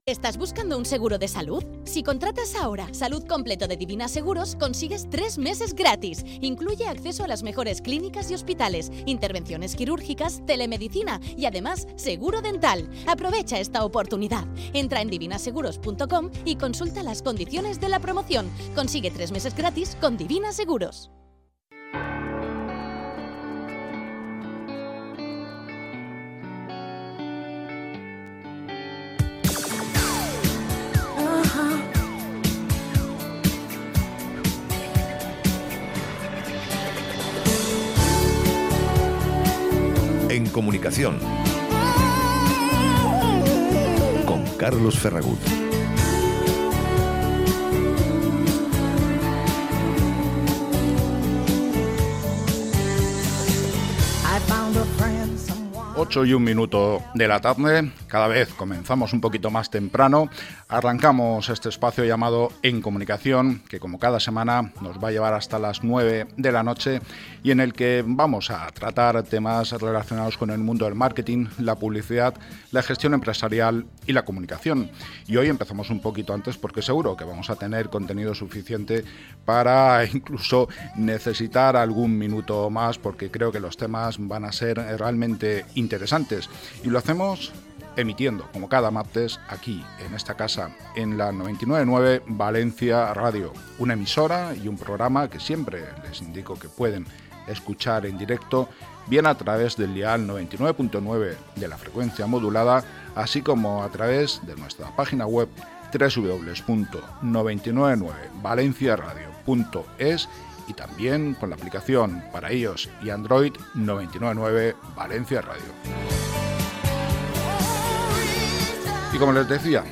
Mantenemos una nueva entrevista a nuestras empresas centenarias españolas